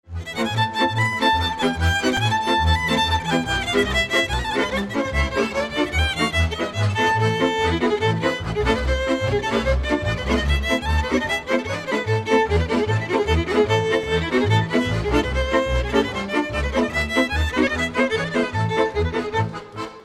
Dallampélda: Hangszeres felvétel
Erdély - Háromszék vm. - Őrkő (Sepsiszentgyörgy)
hegedű
kontra (háromhúros)
bőgő
harmonika
Műfaj: Sebes csárdás
Stílus: 1.1. Ereszkedő kvintváltó pentaton dallamok